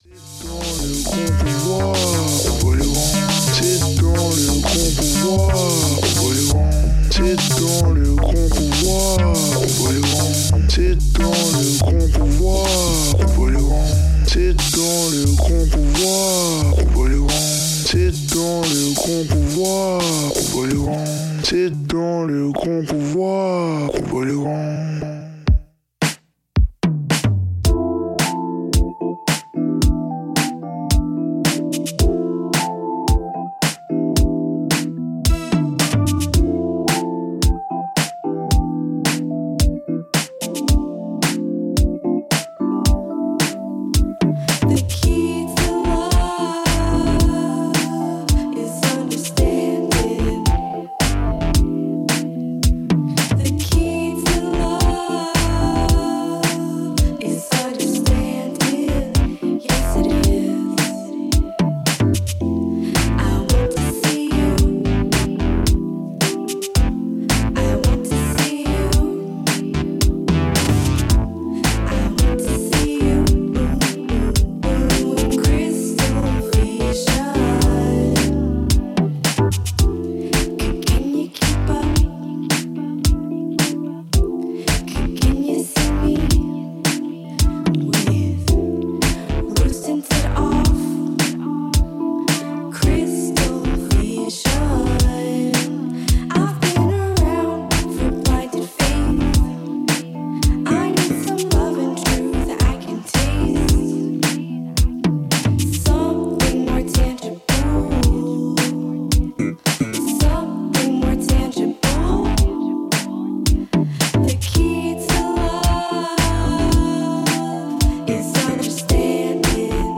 Un set mensuel d'une heure